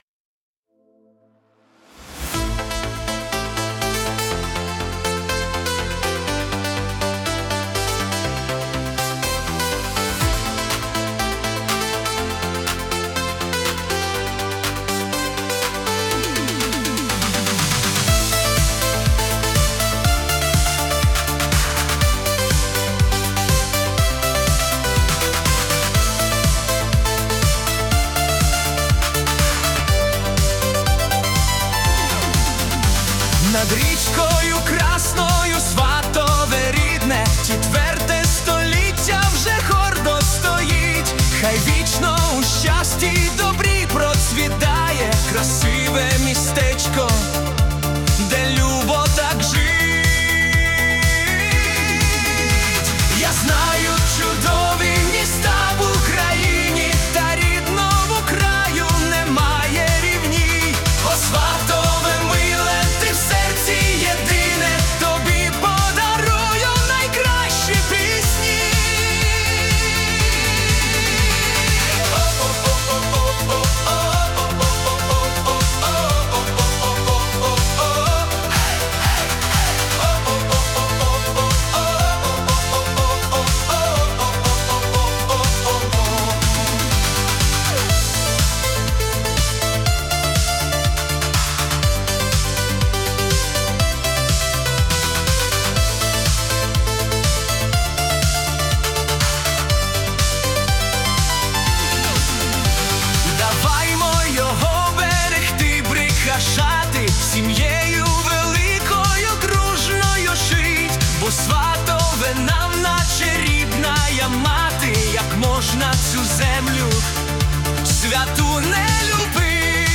🎵 Жанр: Fast Italo Disco